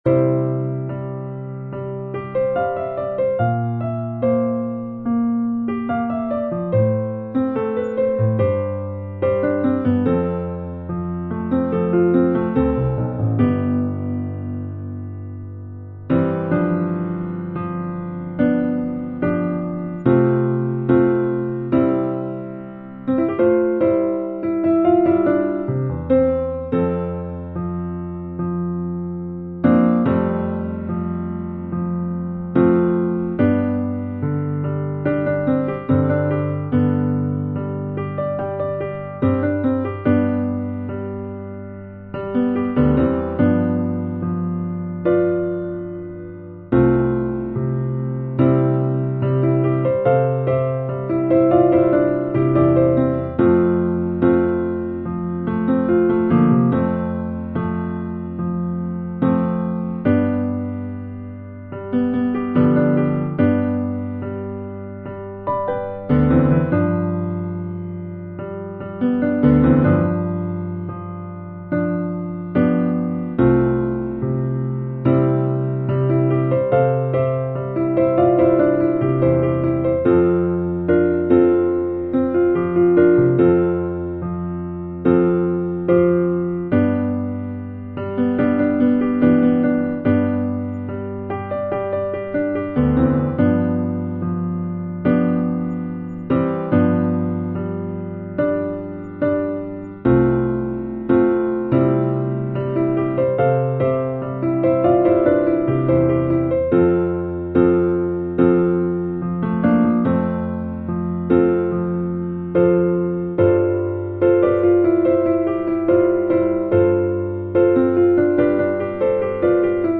Accompaniment.